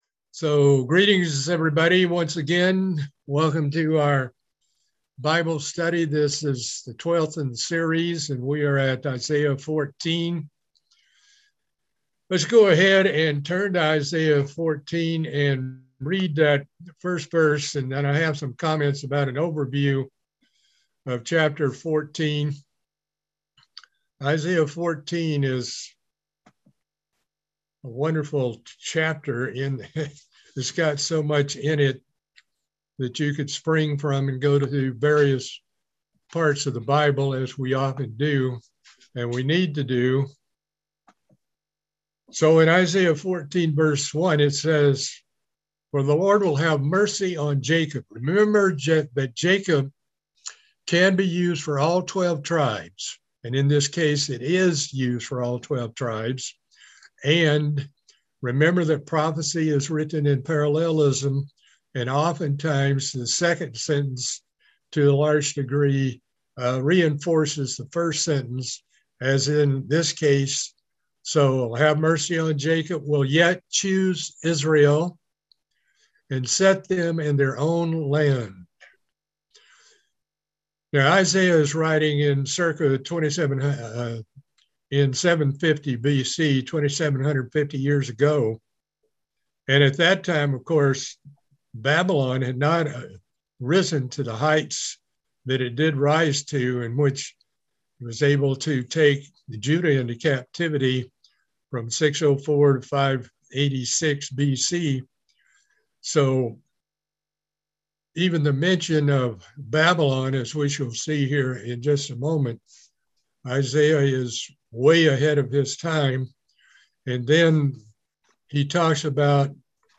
Book of Isaiah Bible Study - Part 12